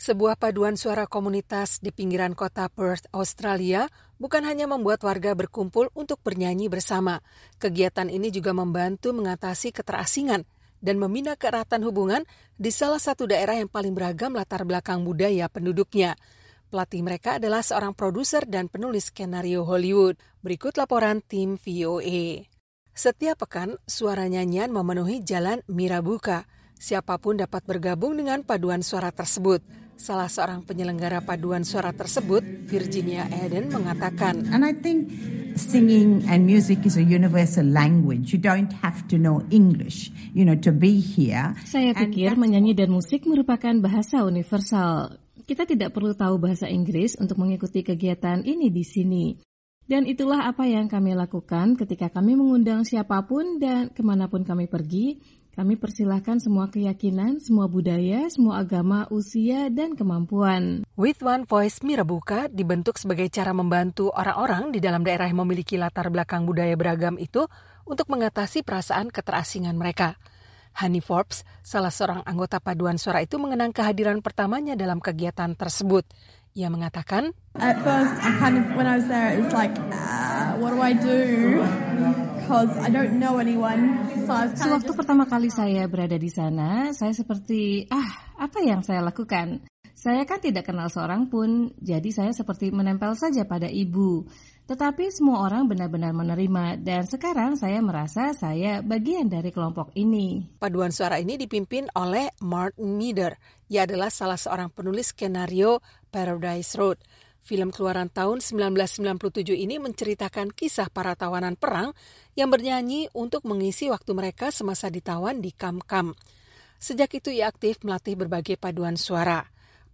Setiap pekan, suara nyanyian memenuhi jalan Mirrabooka.